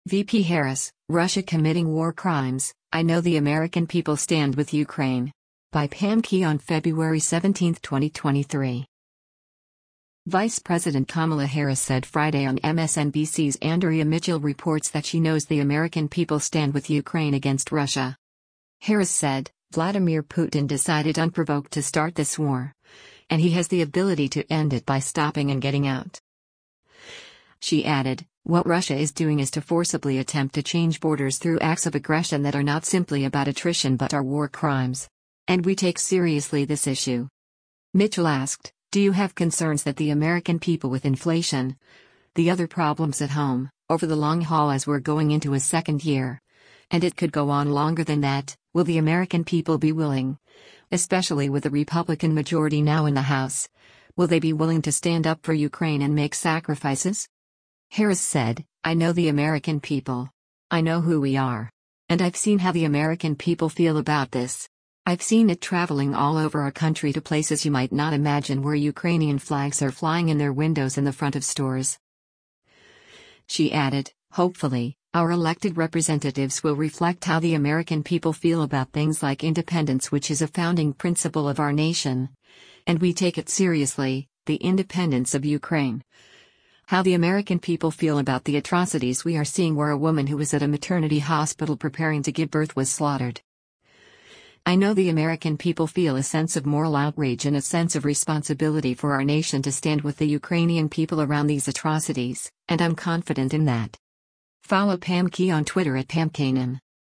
Vice President Kamala Harris said Friday on MSNBC’s “Andrea Mitchell Reports” that she knows the American people stand with Ukraine against Russia.